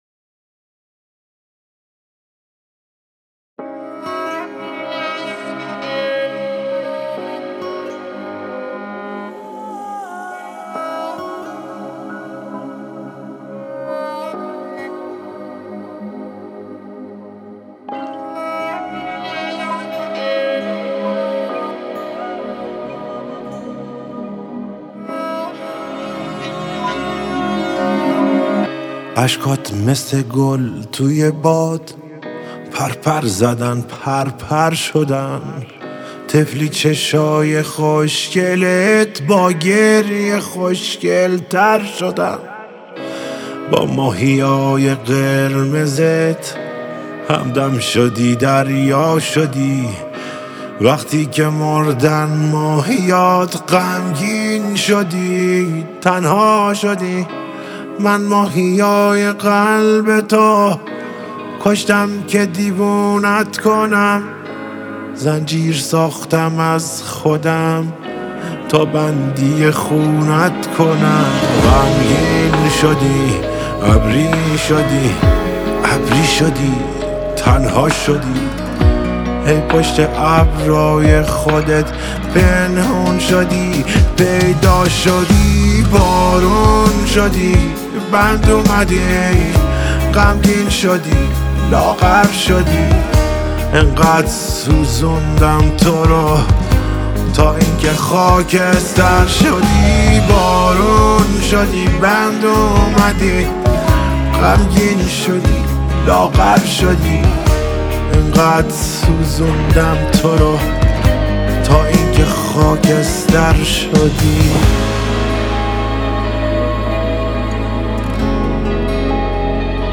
گیتار آکوستیک
گیتار الکتریک
دودوک